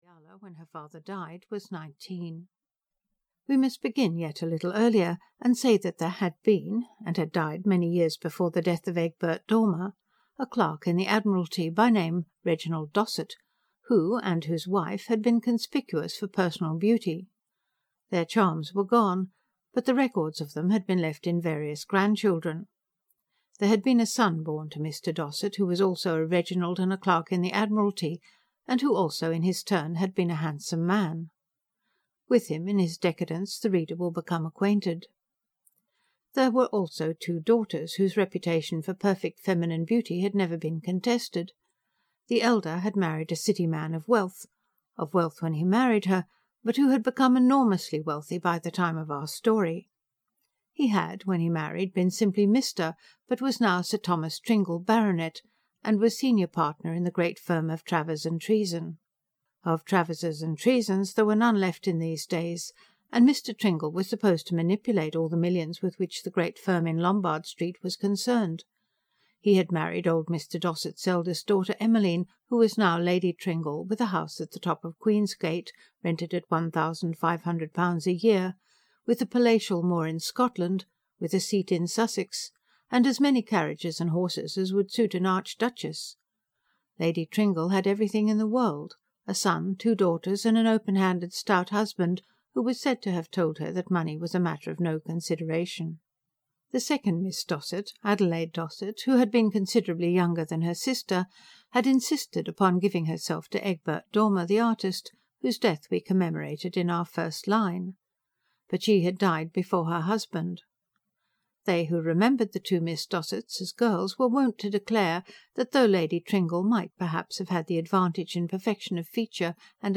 Ayala's Angel (EN) audiokniha
Ukázka z knihy